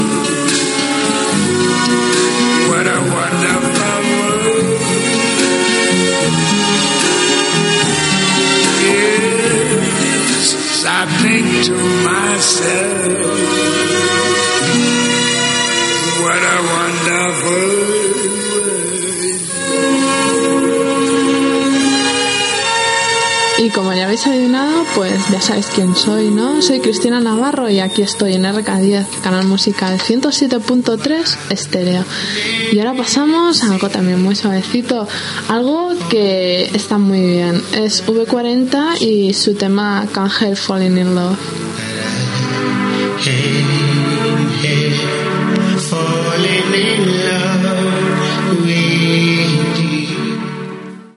Tema musical, identificació i tema musical
FM